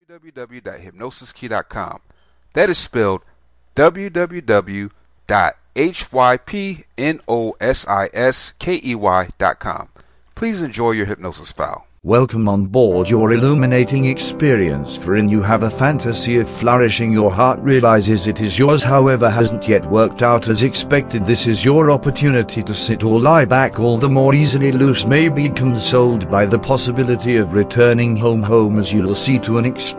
Welcome to prosperity Self Hypnosis Mp3, this is a powerful self hypnosis. This script helps you gain prosperity in your life. This mp3 helps you meditate and feel good in your mind body and spirit.